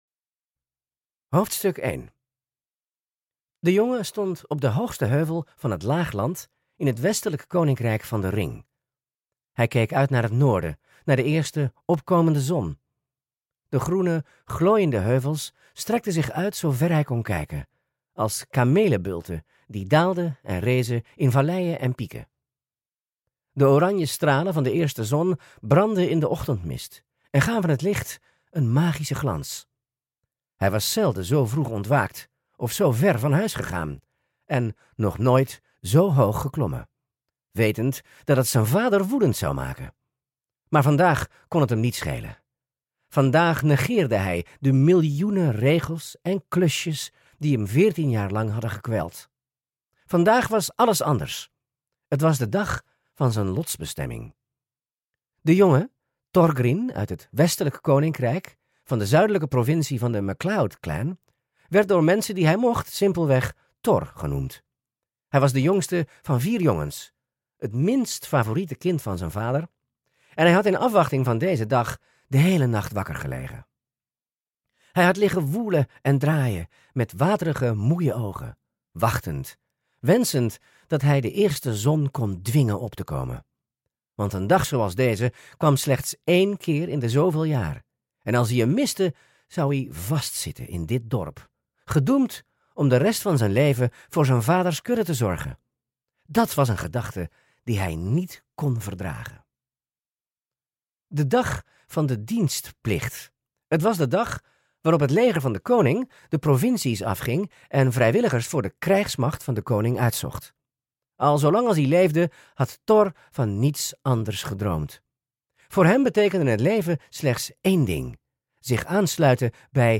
Аудиокнига Een Zoektocht Van Helden | Библиотека аудиокниг